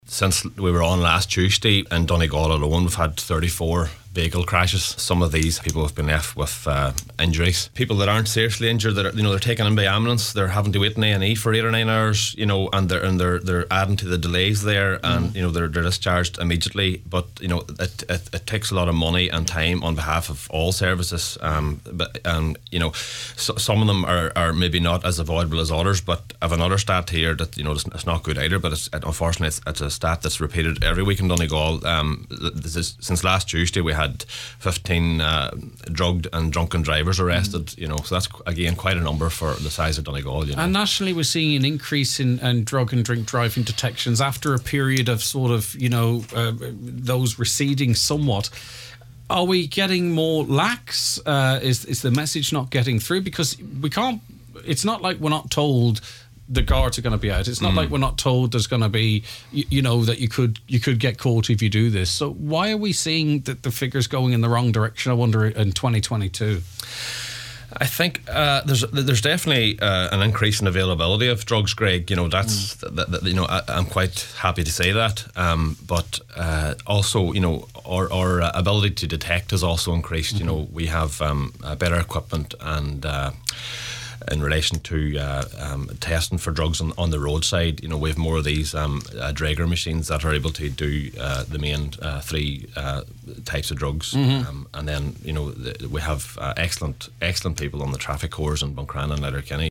A Donegal garda says more people are being detected for drink and drug driving because the capacity of gardai to detect drugs continues to improve.